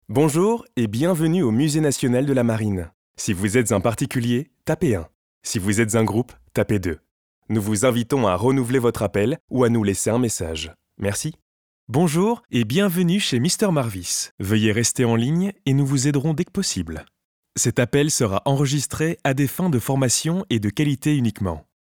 French voice over
Deep, Young, Accessible, Versatile, Natural
Telephony